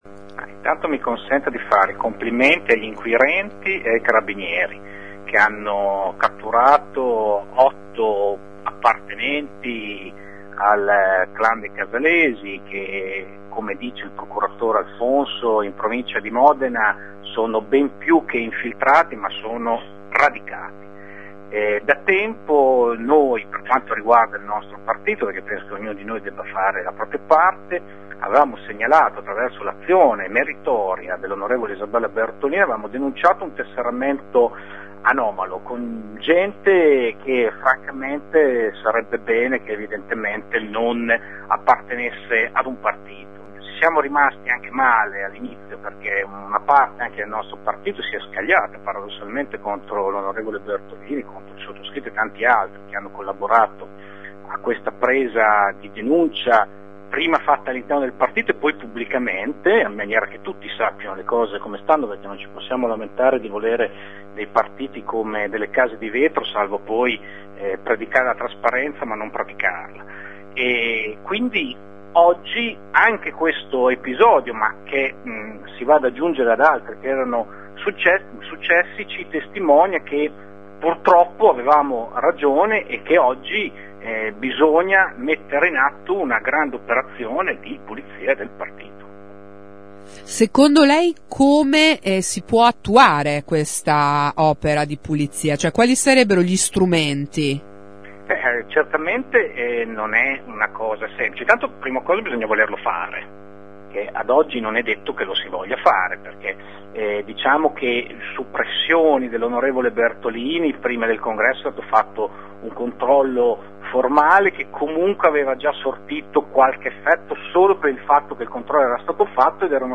Ascolta il consigliere Leoni